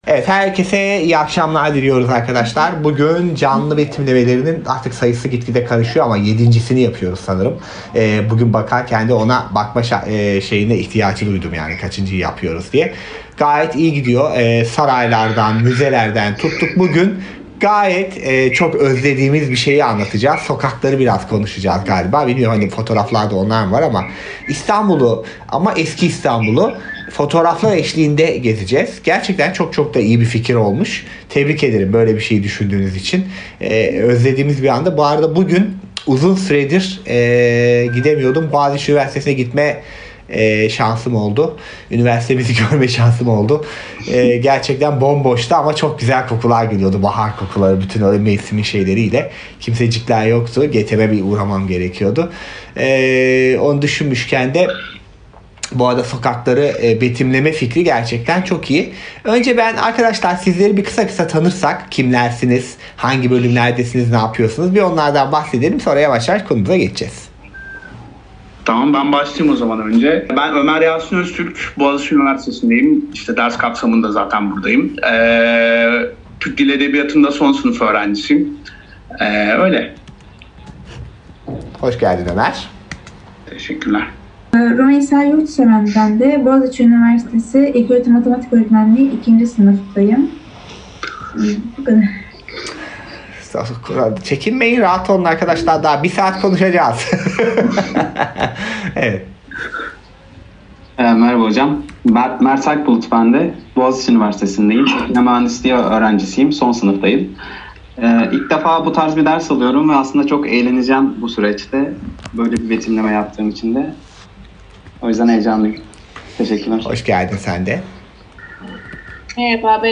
Canlı Betimlemeler